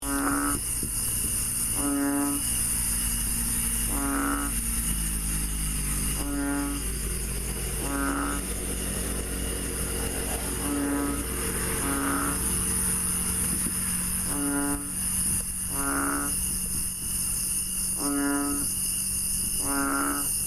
亞洲錦蛙 Kaloula pulchra pulchra
屏東縣 來義鄉 沿山公路來義台糖造林地
車道旁的大水溝,當天大雨
鳴叫